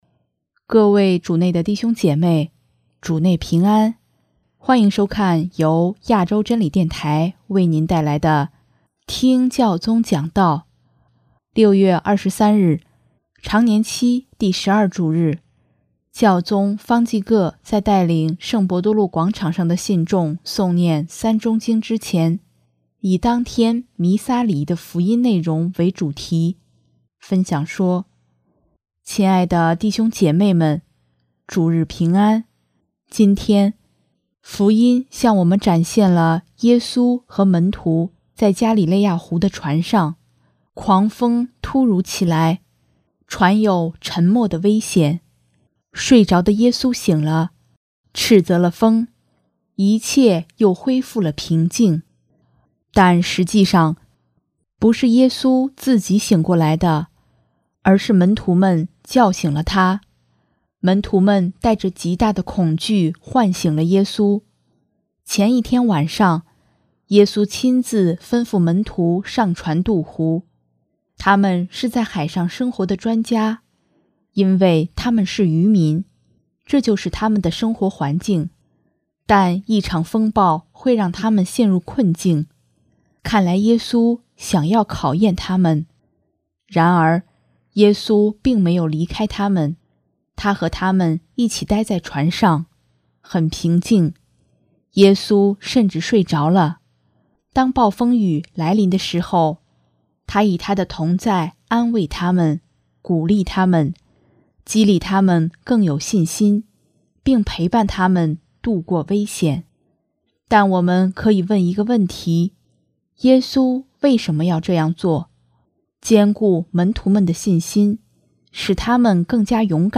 6月23日，常年期第十二主日，教宗方济各在带领圣伯多禄广场上的信众诵念《三钟经》之前，以当天弥撒礼仪的福音内容为主题，分享说：